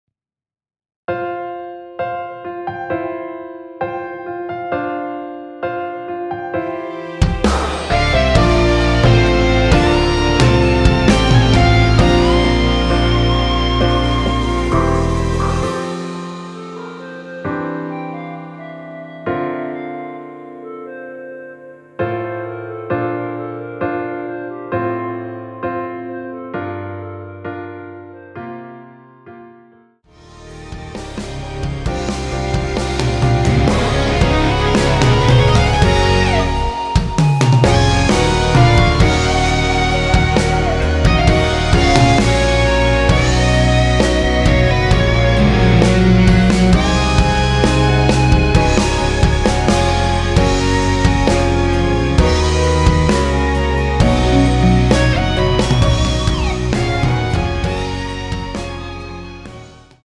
원키에서(+4)올린 멜로디 포함된 MR입니다.(미리듣기 확인)
앞부분30초, 뒷부분30초씩 편집해서 올려 드리고 있습니다.
중간에 음이 끈어지고 다시 나오는 이유는